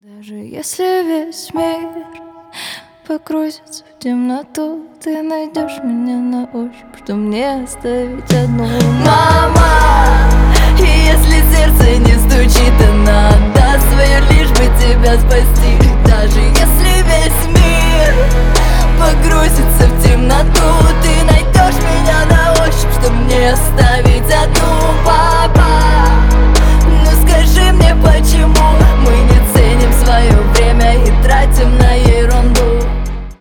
Поп Музыка
громкие # грустные